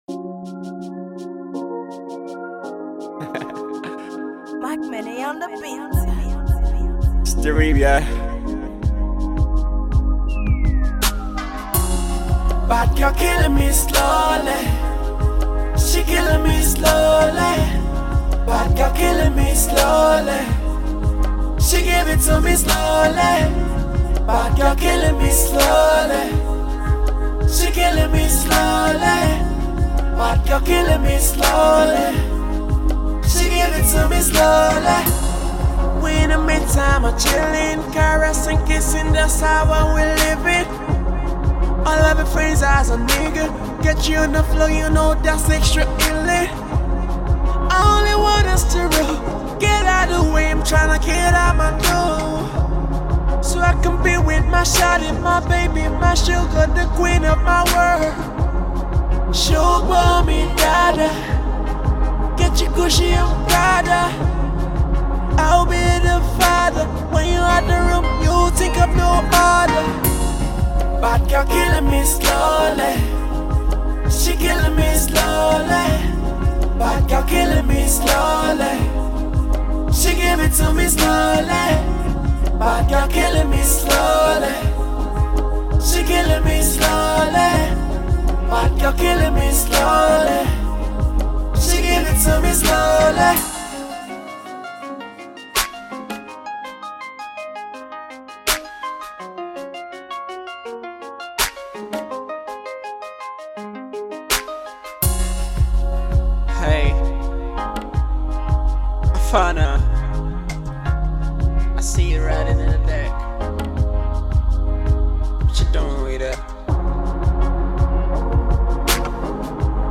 a slow ballad for the wayward Females in the house